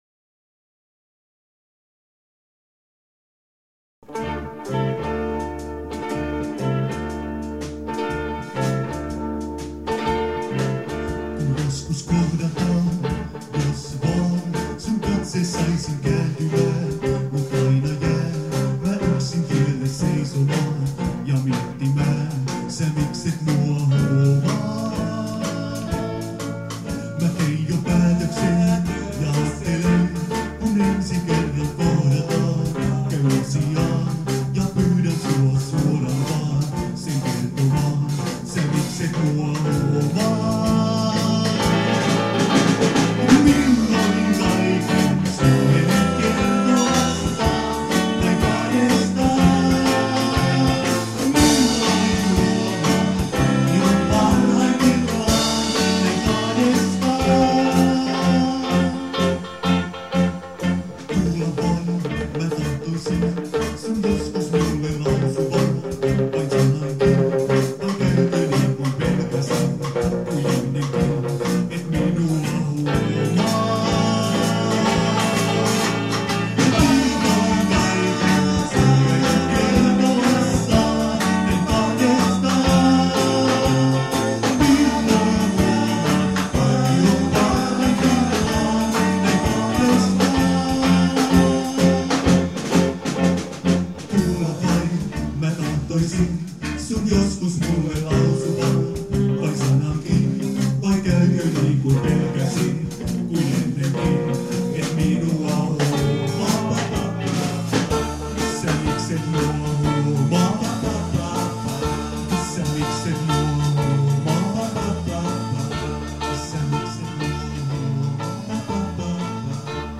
Äänitetty treenikämpällä 2003